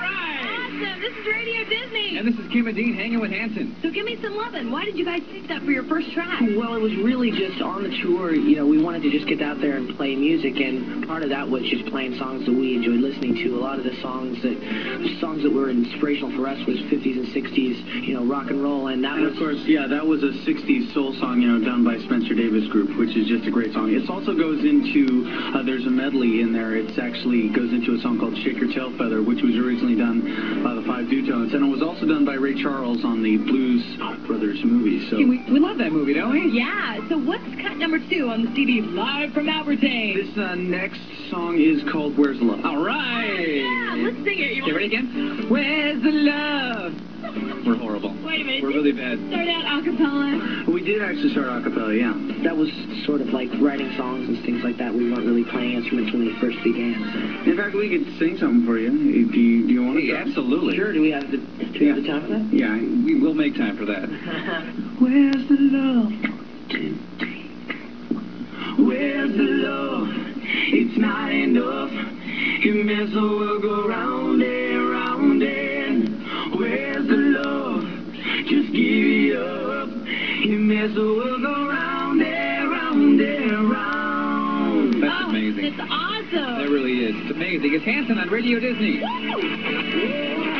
Here's the interview parts of the Radio Disney Special Delivery!